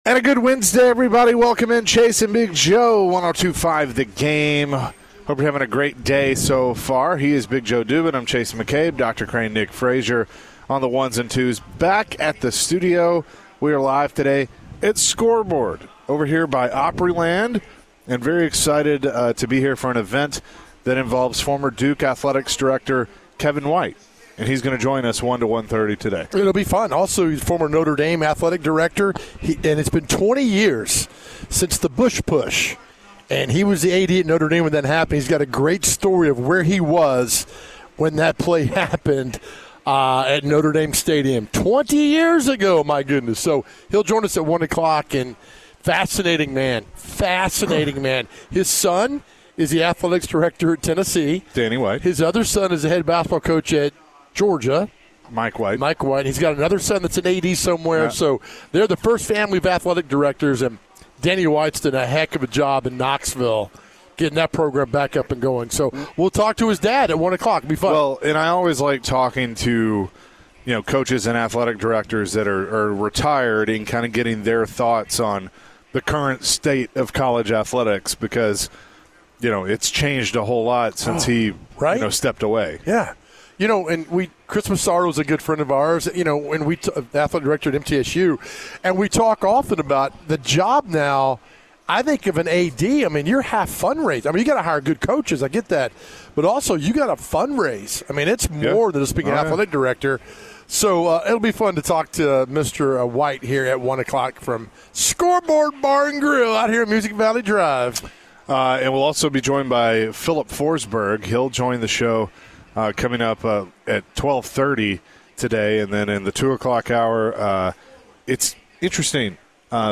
Later in the hour, Nashville Predators forward Filip Forsberg joined the show and shared his thoughts on the current road trip in Canada with the Preds. Filip also shared his thoughts on the young talent in Brady Martin.